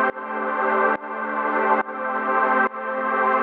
GnS_Pad-dbx1:2_140-A.wav